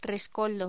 Locución: Rescoldo
voz
Sonidos: Voz humana